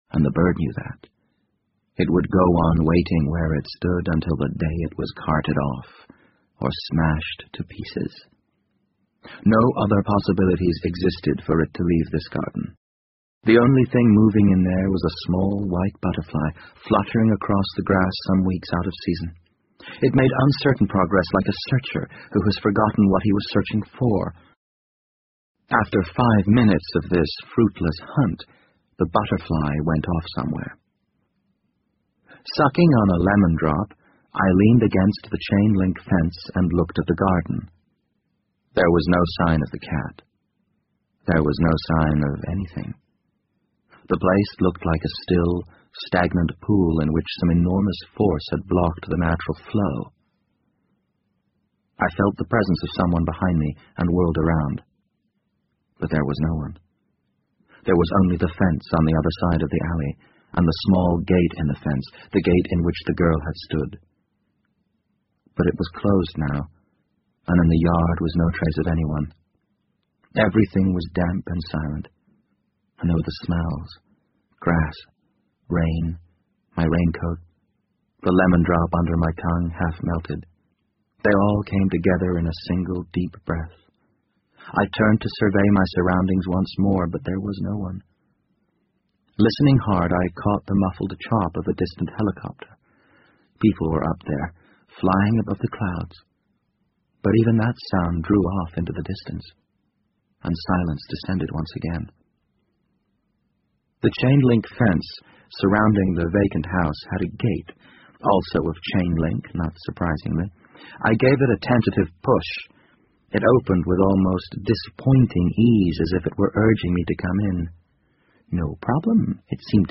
BBC英文广播剧在线听 The Wind Up Bird 29 听力文件下载—在线英语听力室